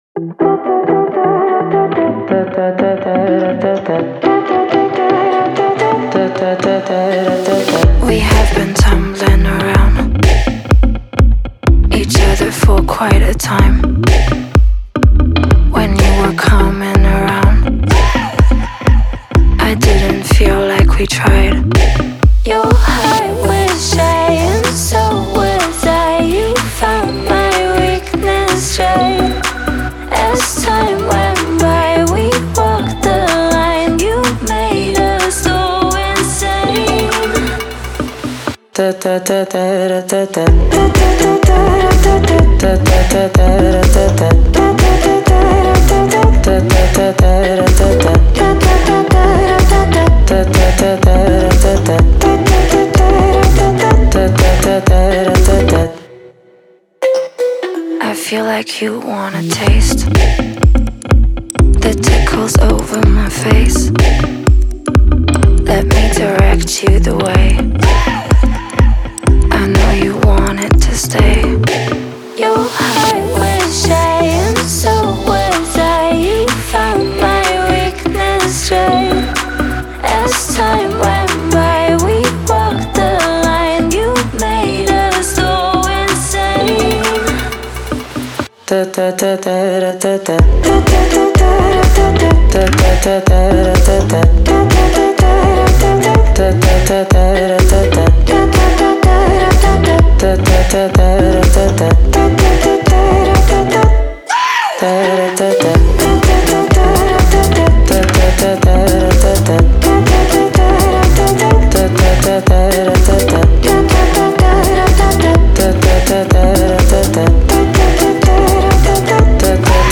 это энергичный трек в жанре электронной танцевальной музыки